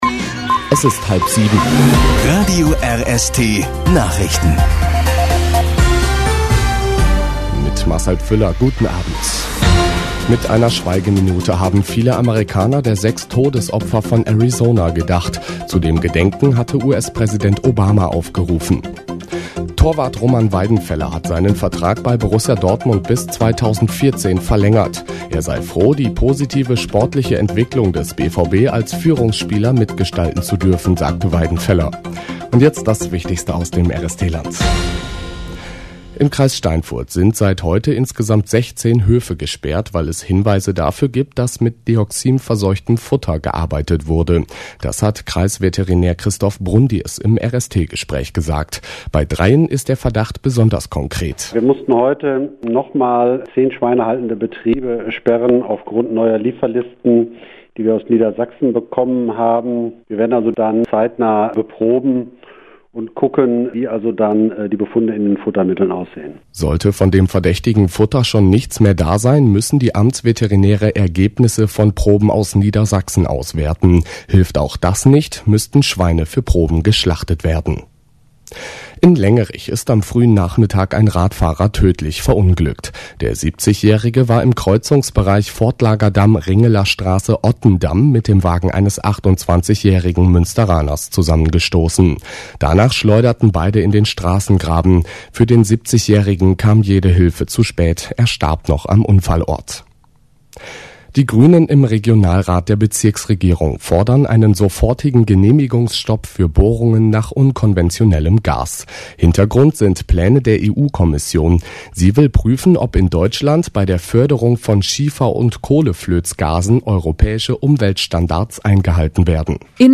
RadioRST Bericht